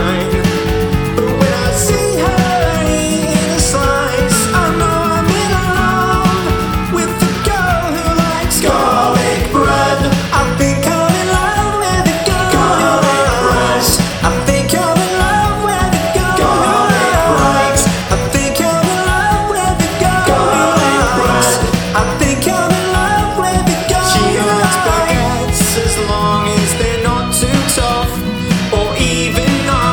• Comedy